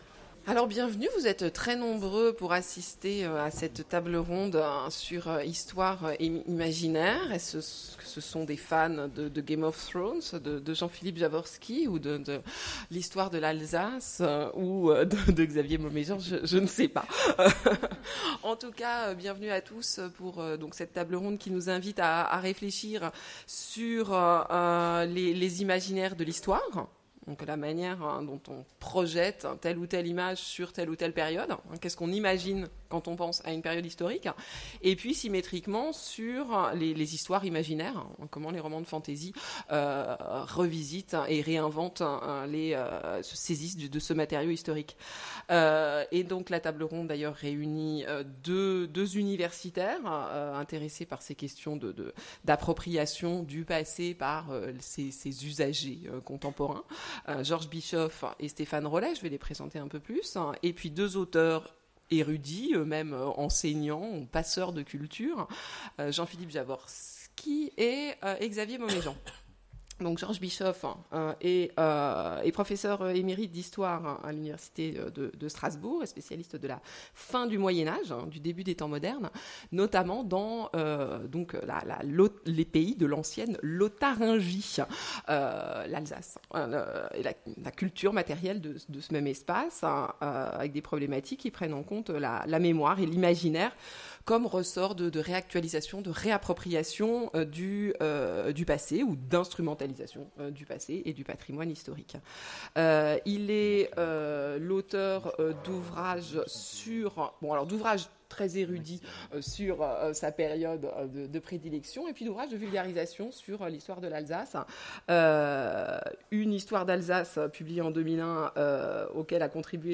Imaginales 2016 : Conférence Histoire et imaginaire